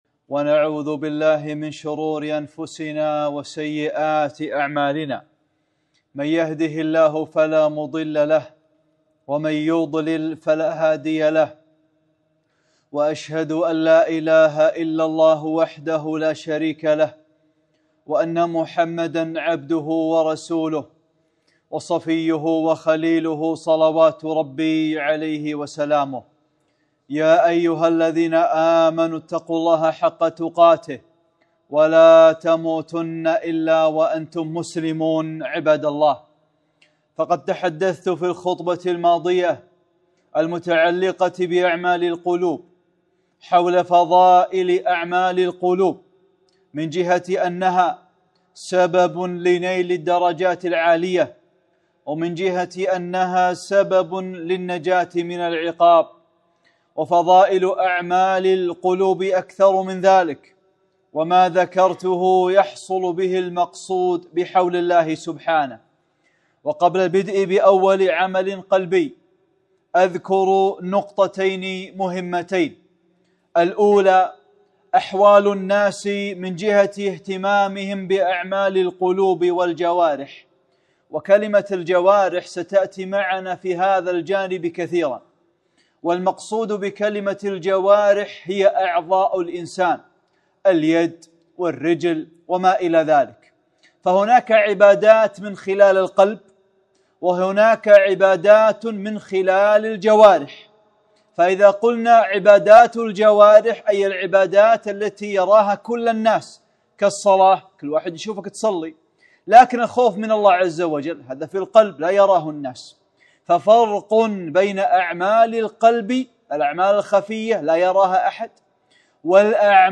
(10) خطبة - ما أهمية الإخلاص؟